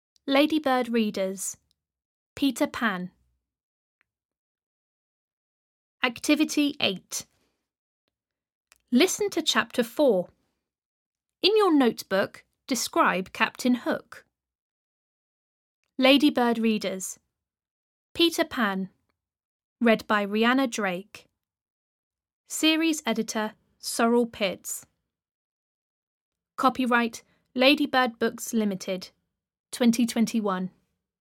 Audio UK